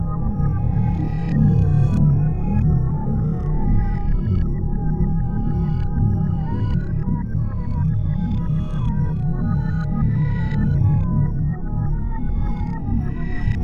portalEnergySound.wav